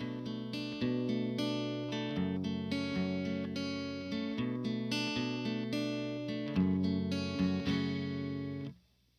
Guitar_Found_110bpm_Bmin.wav